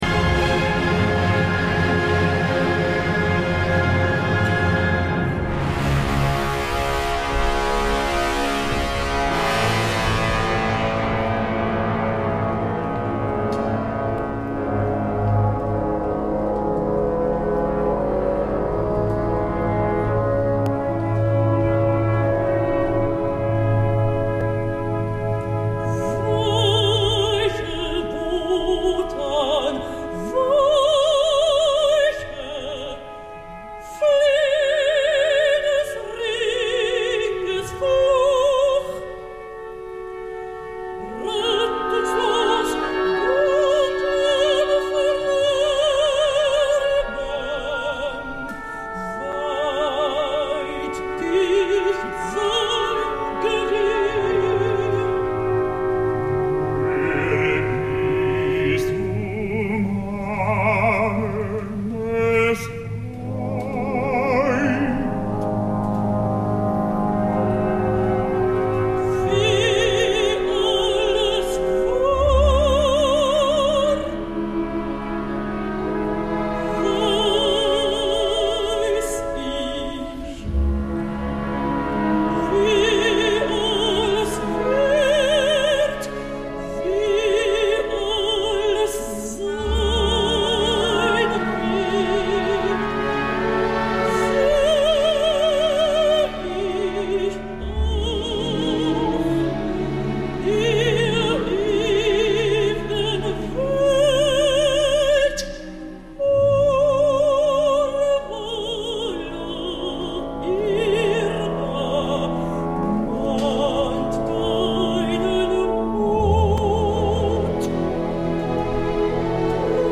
Erda: Mihoko Fujimura
No és una contralt, com requereix el rol, a vegades no sembla ni mezzo, però el seu cant intens i precís, la fa molt notable.
En conjunt, un pròleg molt notable gràcies al director, que amb ma fèrria signa una versió orquestral d’absolut reclinatori.